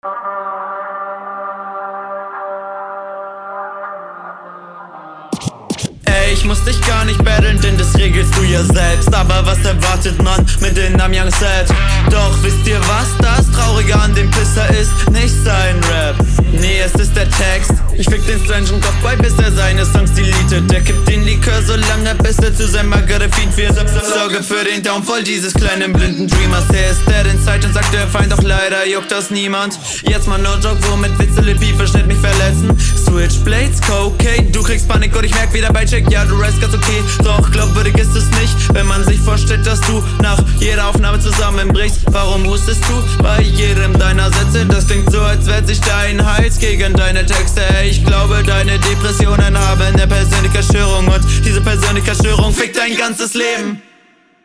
Eine der besser geflowten Runden von dir, die Mische ist ziemlich cool gemacht.